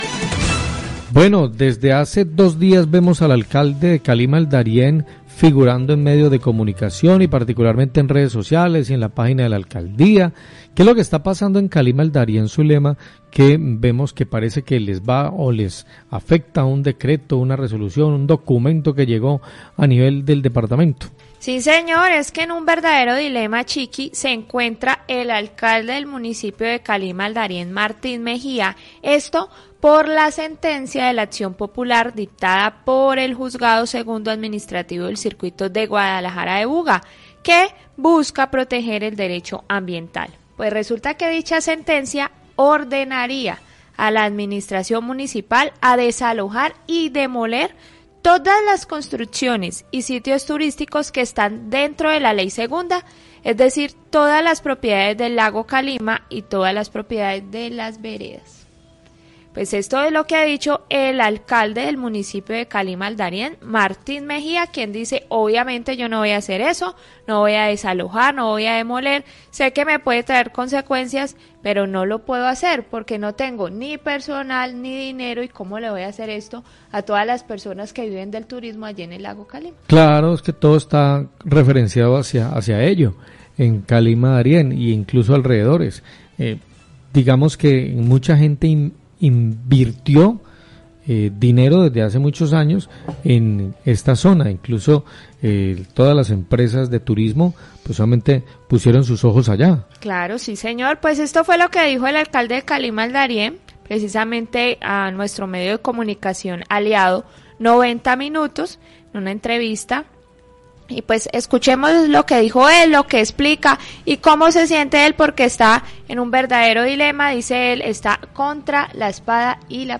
Radio
Declaraciones del Alcalde de Calima-El Darién, Martín Mejia, rechazando este fallo que obliga a demoler las estructuras que no cumplan con la ley.